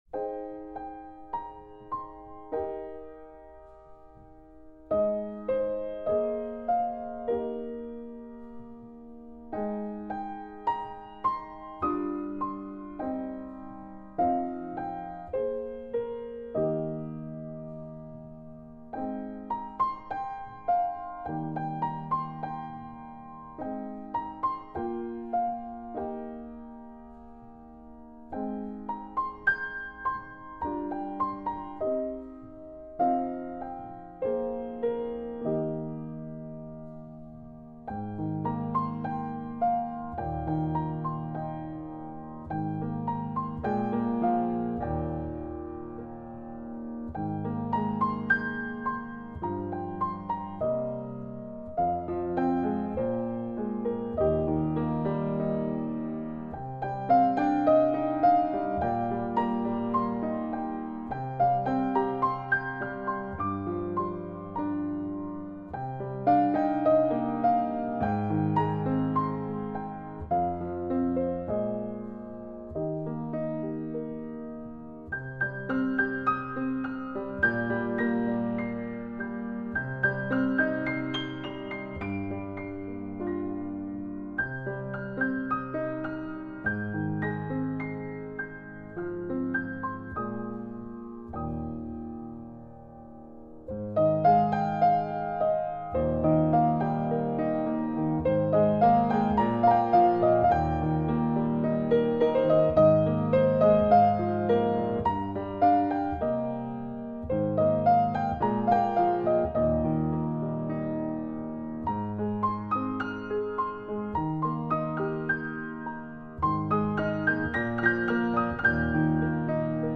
宁静而温暖
演奏音乐系列
宁静、安详、渲染着温柔的疗癒之光
用细腻的触键诠释隽永的旋律。